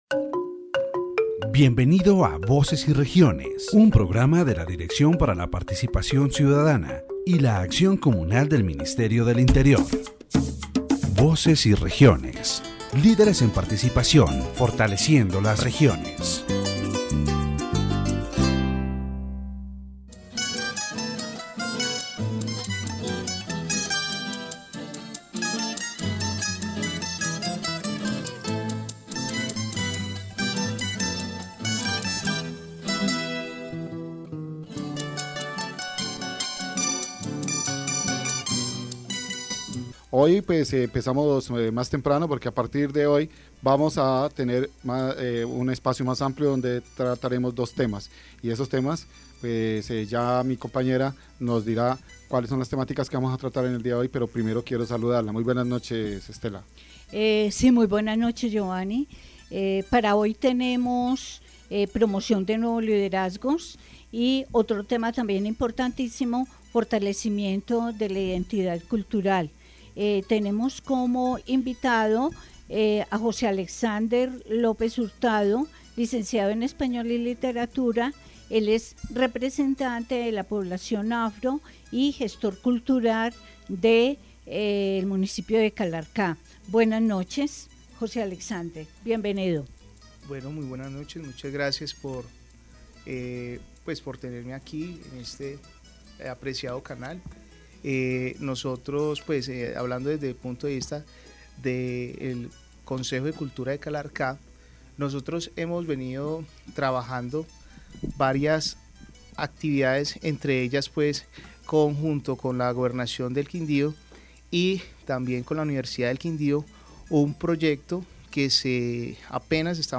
In this edition of Voces y Regiones, the promotion of new leadership in the department is discussed, with a focus on the participation of young Afro-descendant populations. The interviewee encourages young people to engage in spaces for participation and education to support community development.